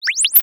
maximize_004.wav